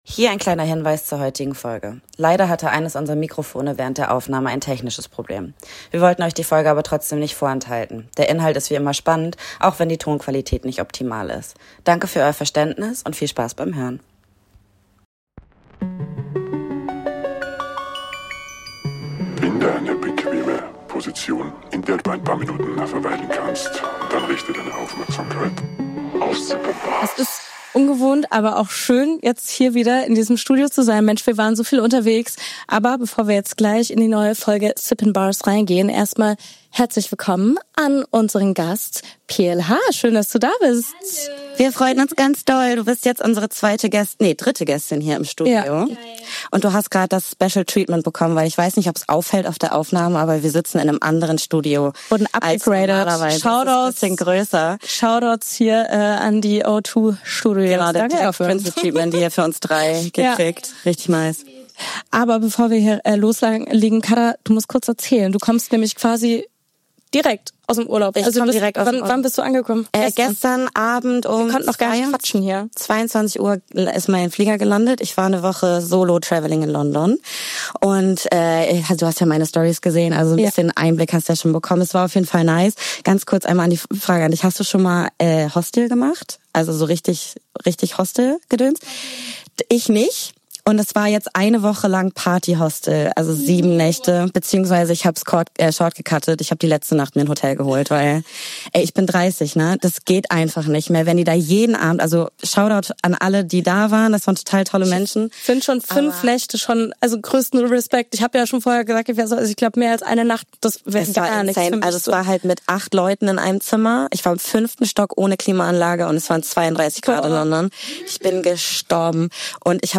Beschreibung vor 7 Monaten Wir sind zurück im Stu!